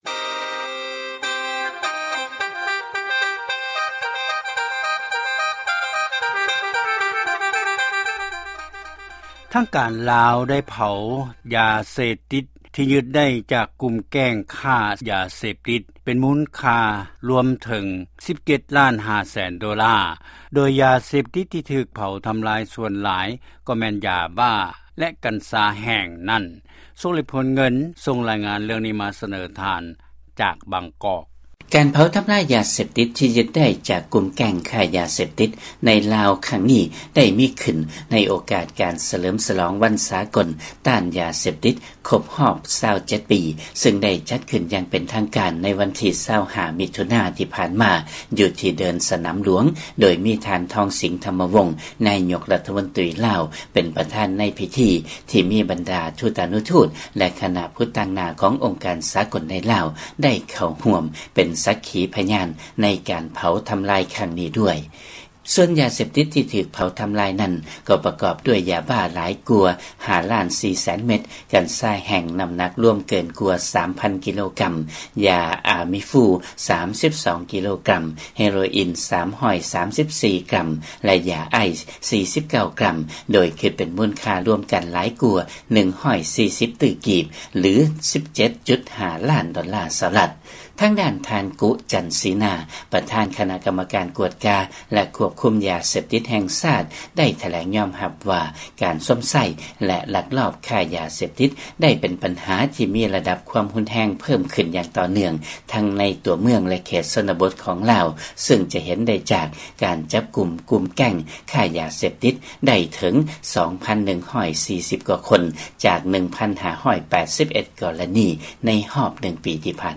ຟັງລາຍງານເລື້ອງ ທາງການລາວ ໄດ້ເຜົາທຳລາຍຢາເສບຕິດ ທີ່ຢຶດໄດ້ ຈາກກຸ່ມແກັ່ງຄ້າຢາເສບຕິດ.